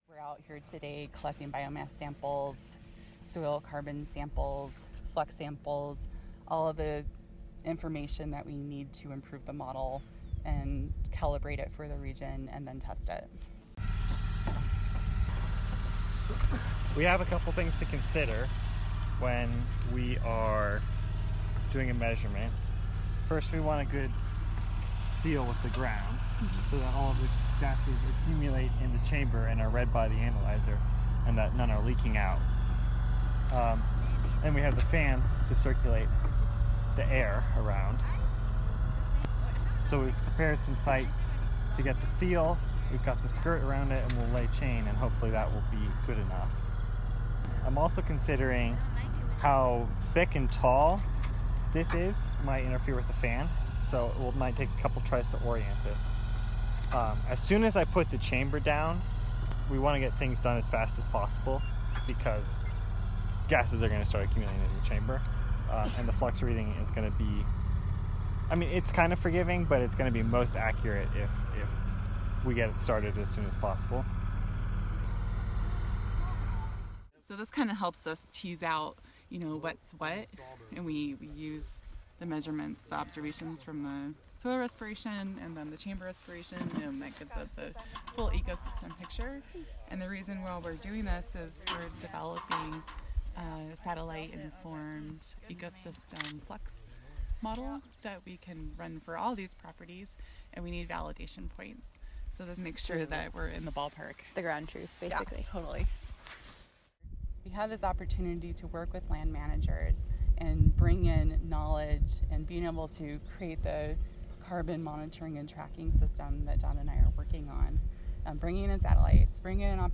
taking measurements using the flux chamber